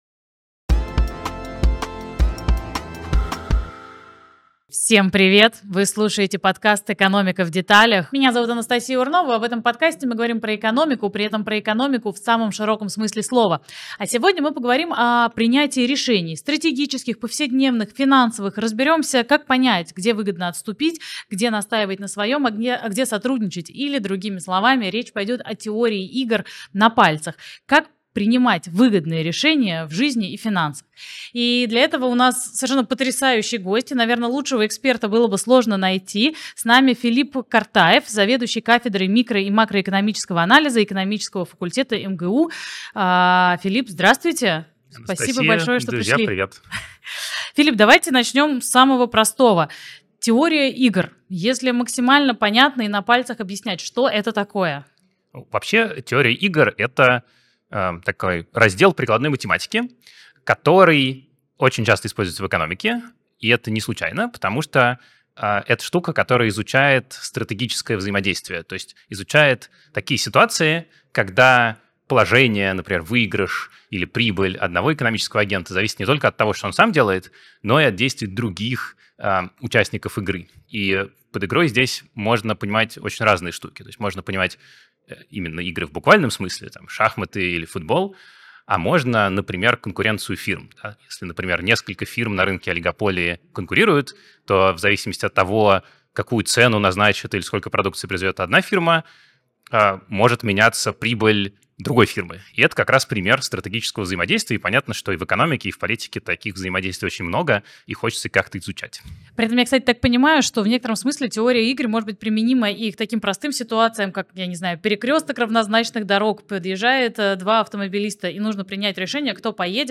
Подкаст «Экономика в деталях» — цикл бесед об устройстве городской экономики и о грамотном подходе к жизни и самореализации в мегаполисе.